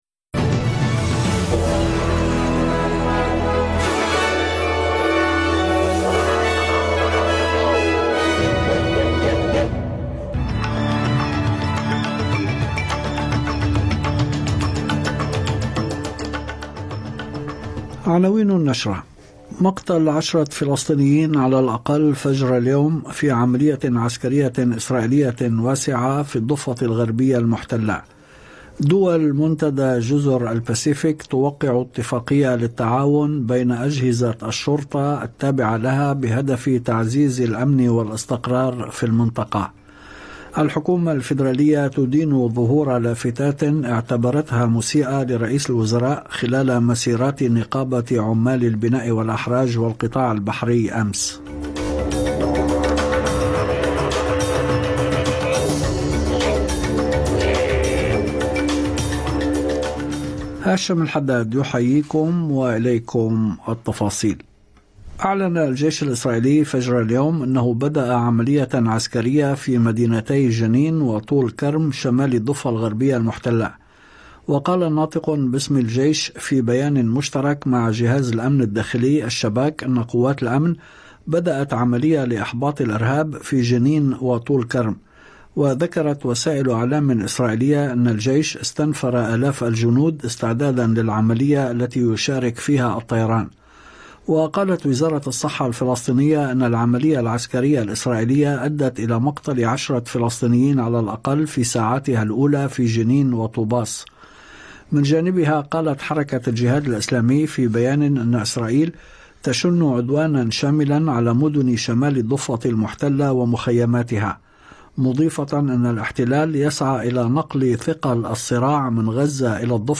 نشرة أخبار المساء 28/8/2024